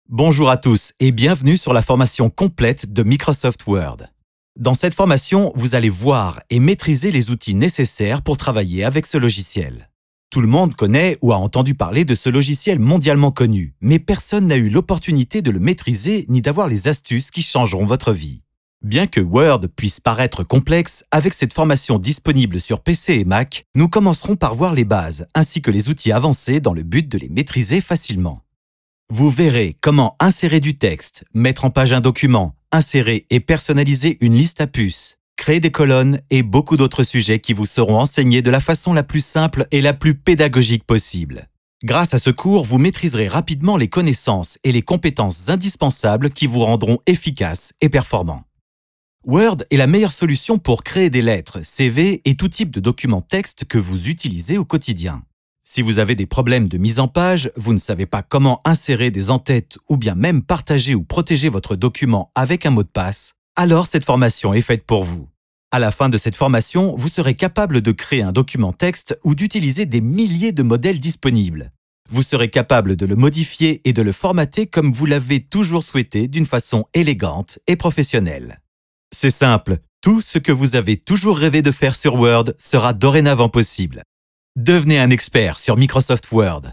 Naturelle, Enjouée, Polyvalente, Mature, Amicale
Vidéo explicative